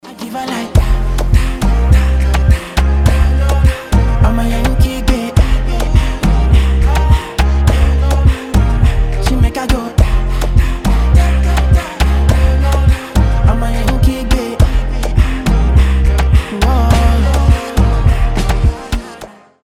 • Качество: 320, Stereo
ритмичные
заводные